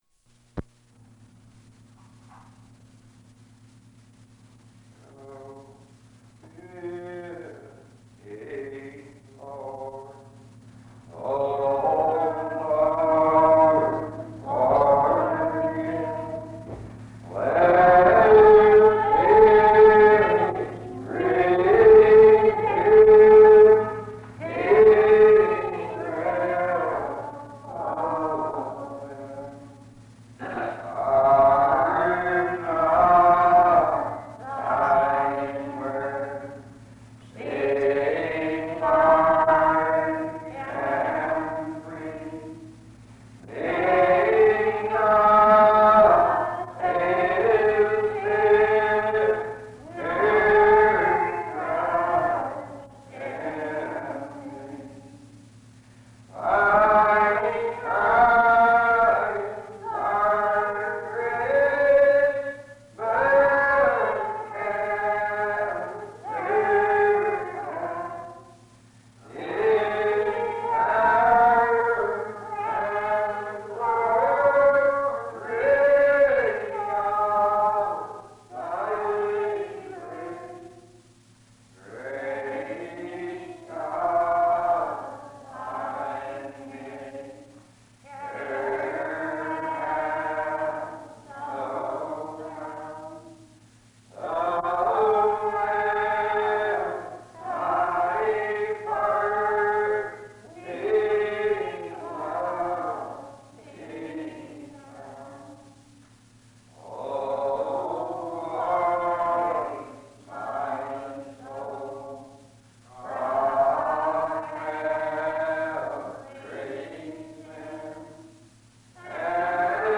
Psalm 84:8-12, by an unknown speaker